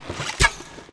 archer_attk_b.wav